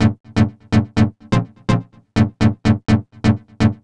cch_synth_loop_motorcity_125_Bb.wav